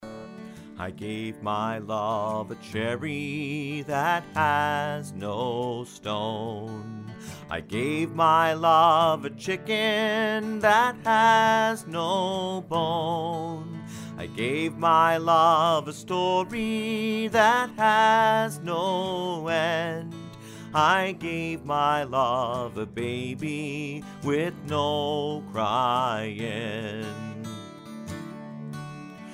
Traditional Children's Song Lyrics and Sound Clip